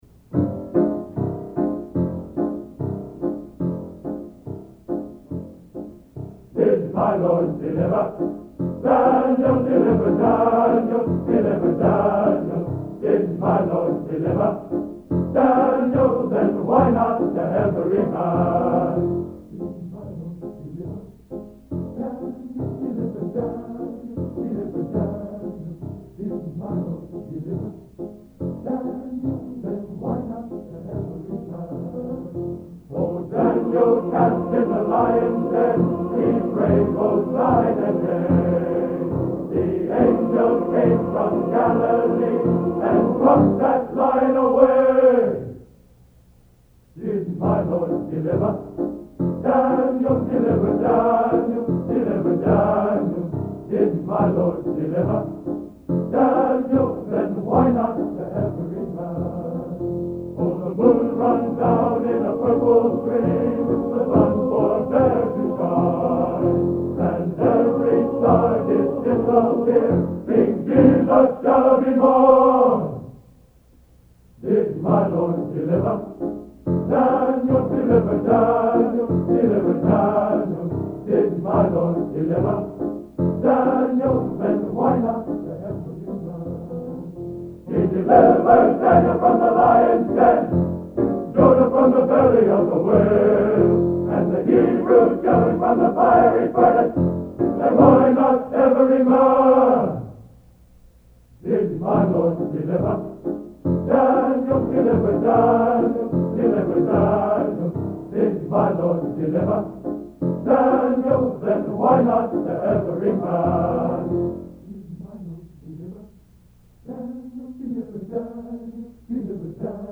Collection: End of Season, 1971
Location: West Lafayette, Indiana
Genre: Spiritual | Type: End of Season